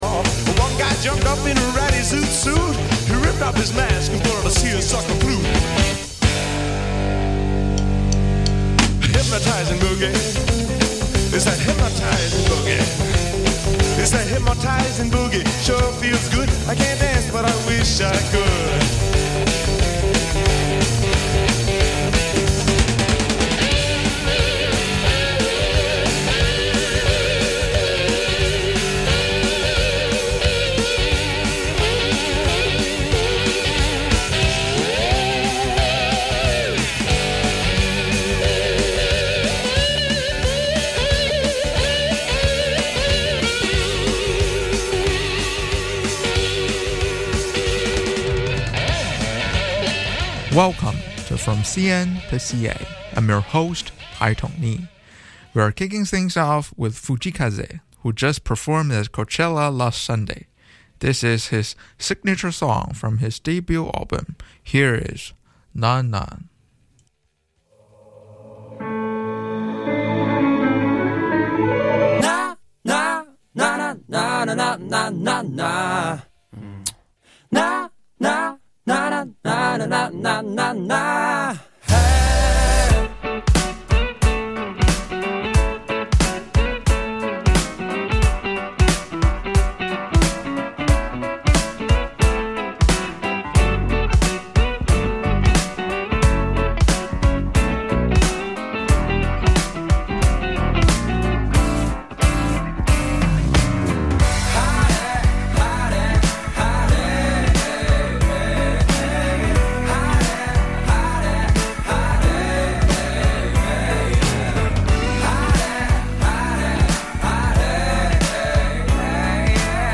Highlighting the best of the Pacific Rim, specifically focusing on C-pop (Mandarin/Cantonese) and J-pop (Japanese).